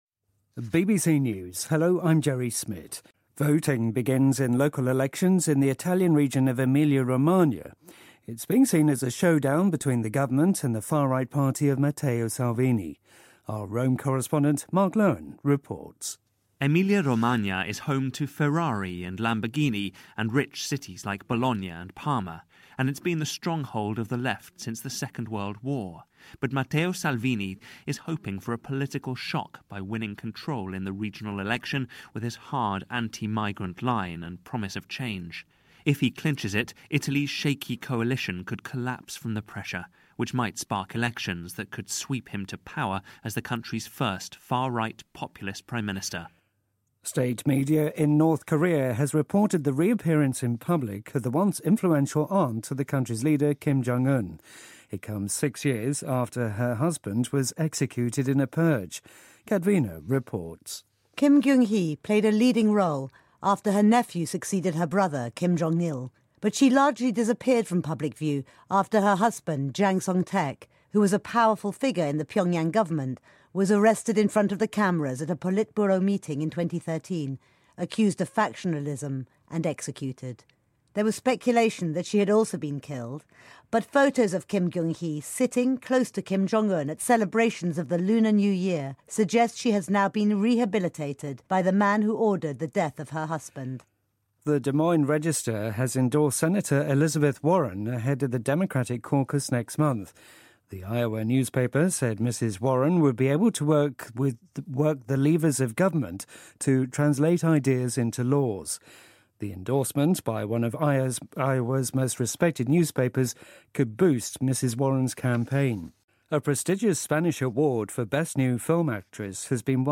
英音听力讲解:美网球选手可可高夫在澳网被淘汰